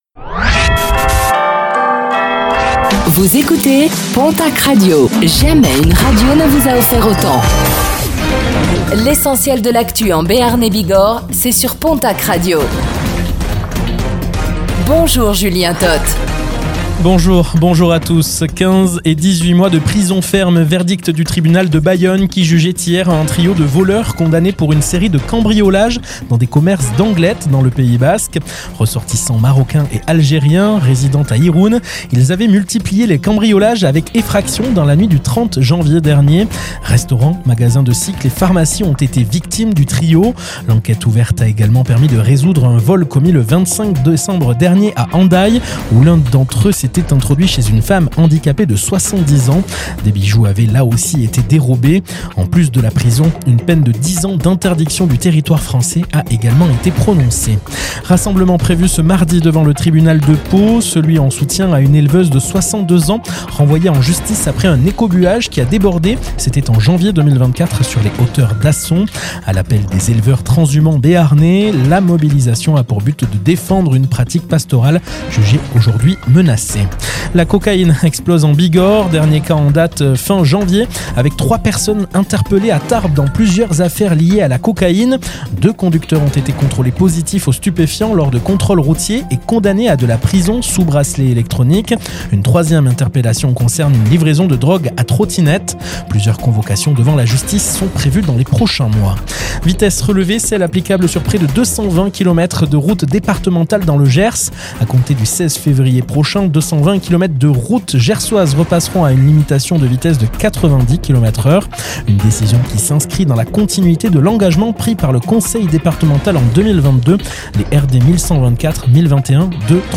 Réécoutez le flash d'information locale de ce mardi 03 février 2026